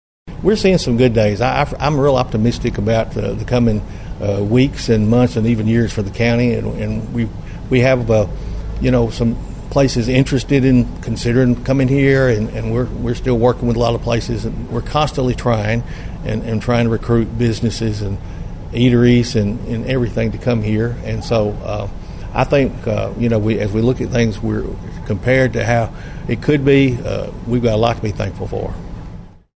Mayor Carr said he also feels positive about the future of Obion County going forward.(AUDIO)